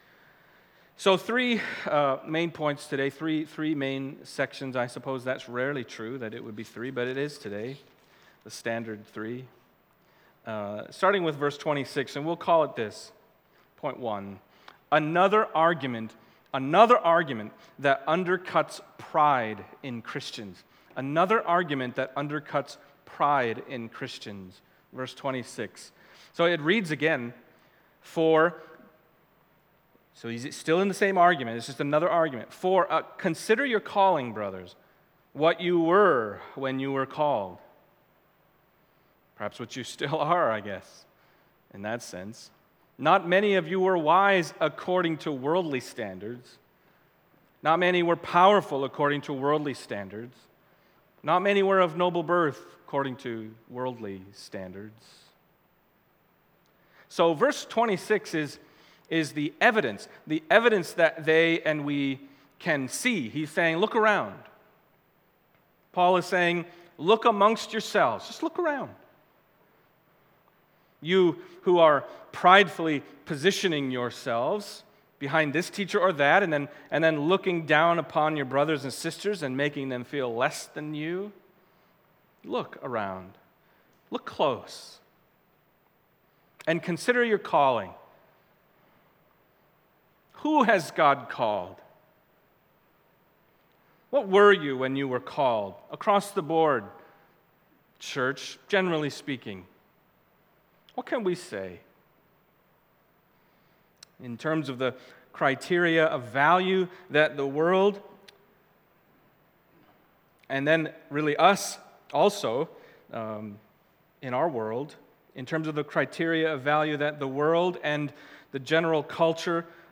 Passage: 1 Corinthians 1:26-31 Service Type: Sunday Morning